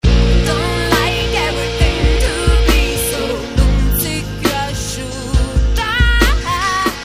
voce e campioni
pianoforte, campionatore e programmazione
batteria, chitarre trattate e campioni
bassi elettronici e contrabasso